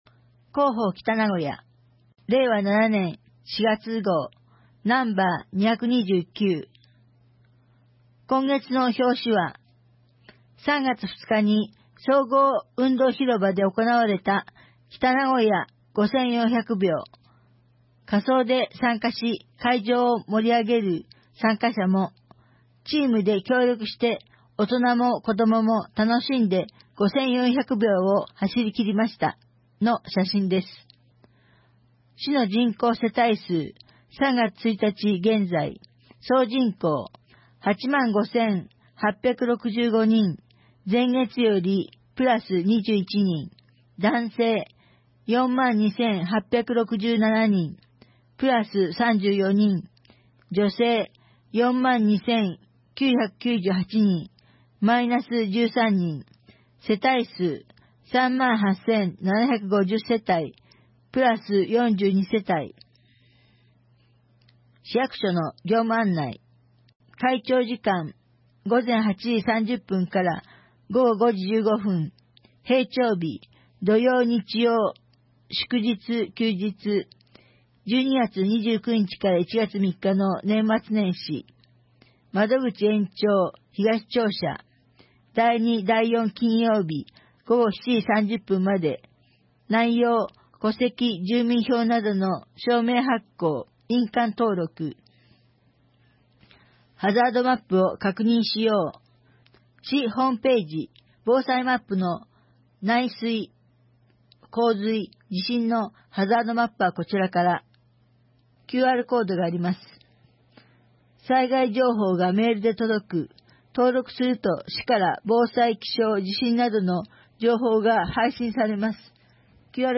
2025年4月号「広報北名古屋」音声版